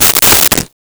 Wood Crack 03
Wood Crack 03.wav